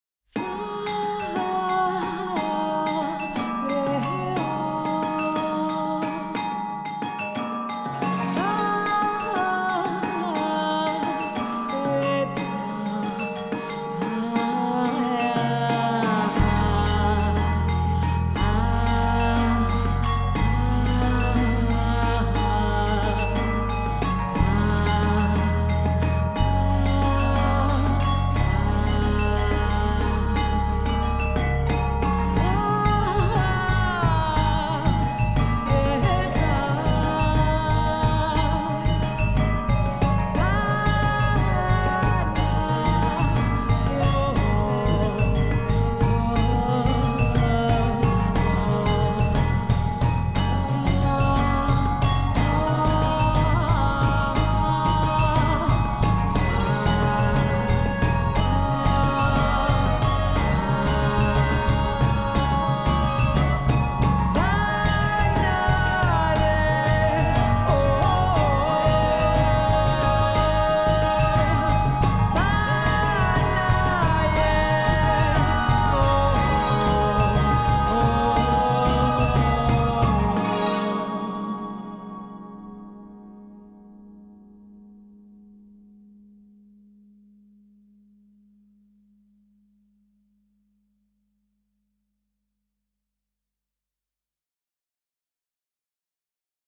Tuned percussion library
Globe-spanning melodies